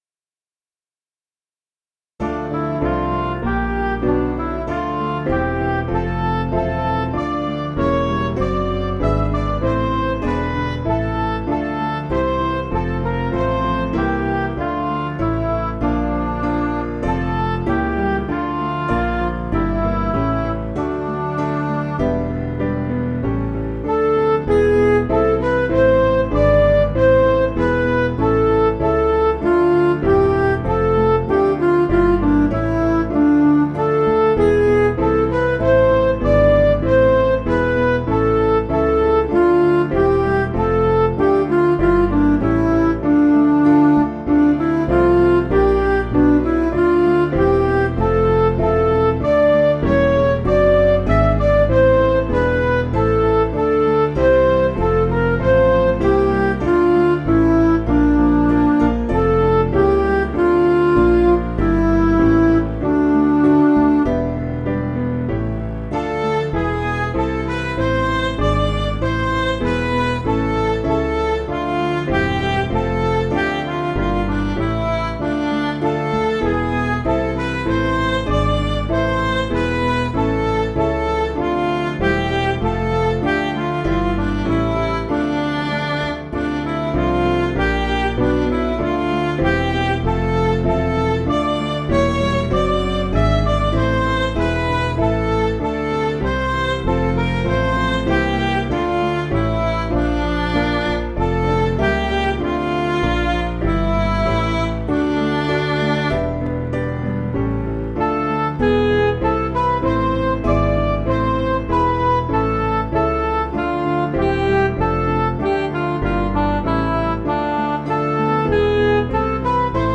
Piano & Instrumental
(CM)   4/Dm